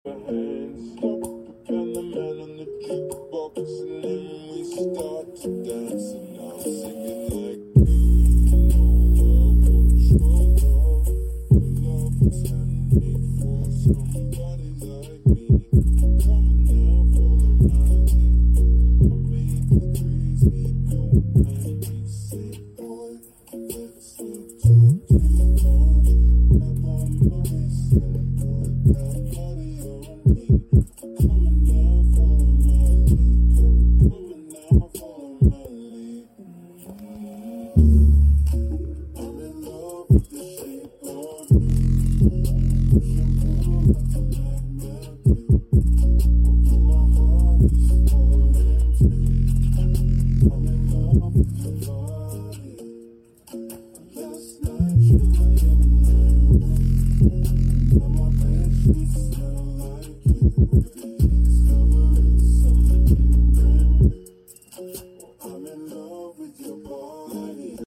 JBL PULSE 3 Vol:60% LFM:ON sound effects free download